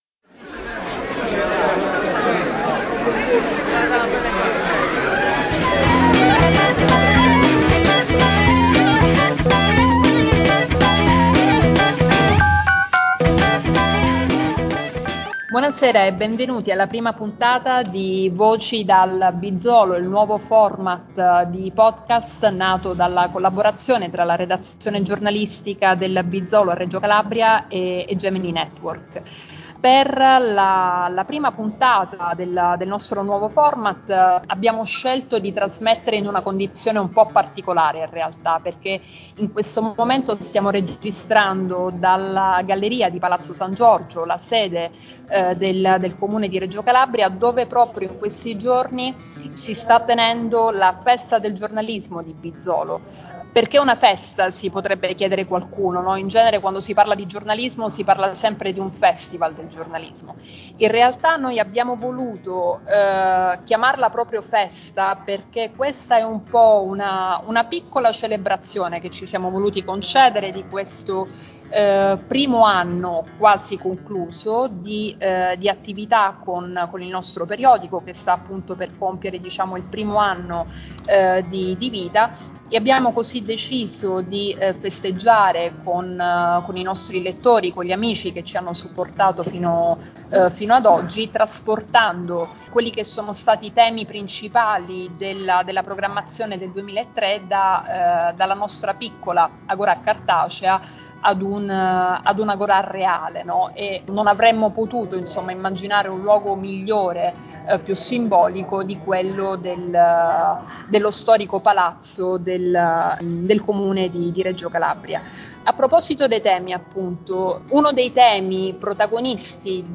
In questa prima puntata, registrata in diretta nel corso della prima edizione di bizzòlo – una festa del giornalismo